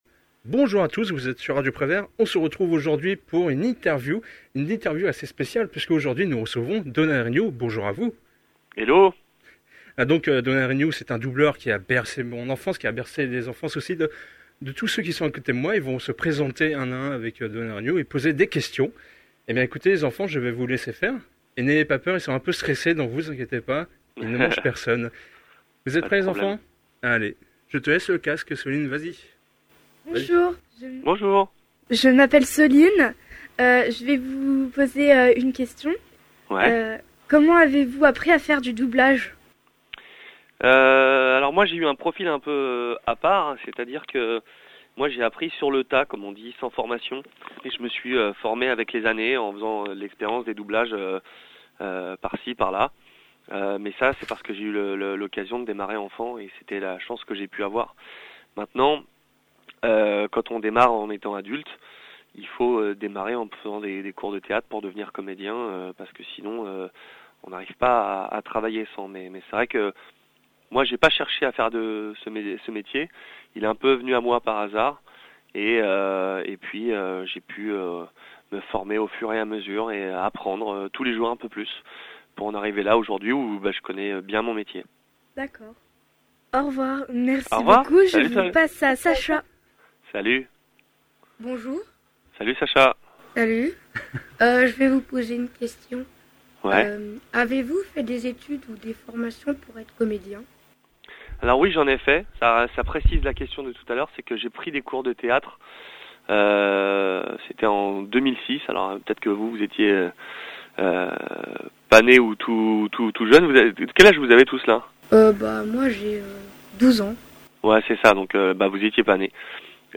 Les Radioteurs ont interviewé Donald Reignoux !